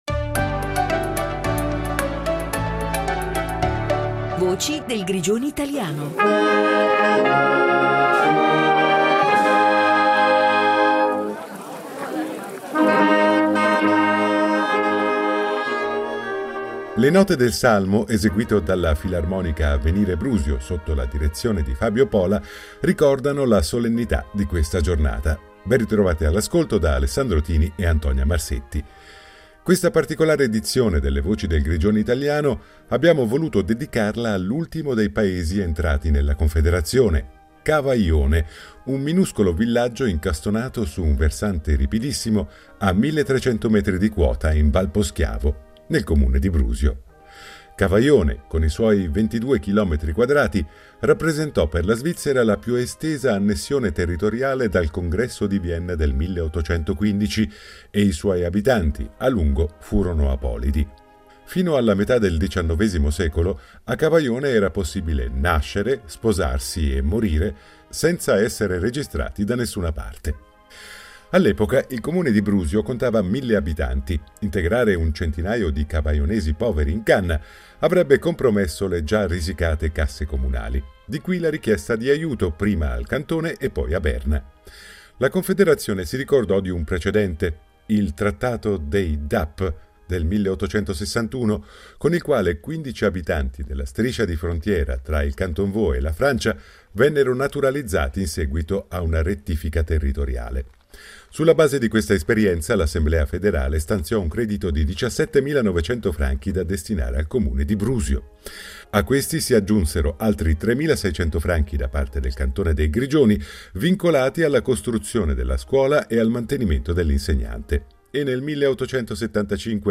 Voci del Grigioni italiano Cavaione Una storia di confini, identità e appartenenza 01.08.2025 23 min Imago Images Contenuto audio Disponibile su Scarica Le solenni note della Filarmonica Avvenire Brusio aprono una puntata speciale dedicata all’ultimo villaggio entrato nella Confederazione: Cavaione, minuscolo borgo a 1’300 metri d’altitudine, oggi parte del Comune di Brusio.
Con il sindaco di Brusio Pietro della Cà ripercorriamo anche un’altra tappa fondamentale: la costruzione della strada, completata nel 1971 dopo sette anni di lavori, che ha spezzato l’isolamento secolare del paese.